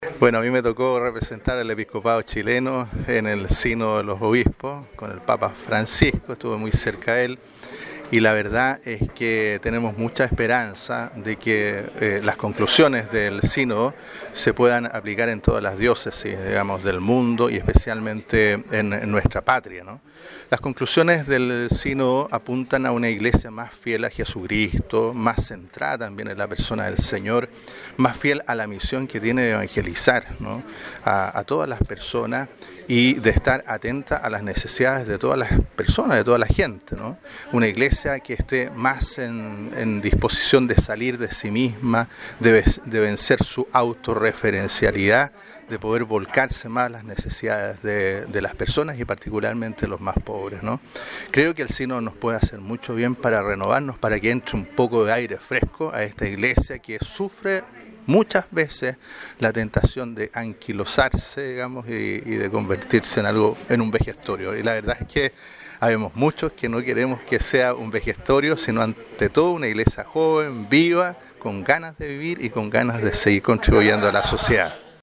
Una mañana fraterna y compartida vivieron representantes de medios de comunicación de Osorno con el obispo de la diócesis católica, monseñor Carlos Godoy Labraña.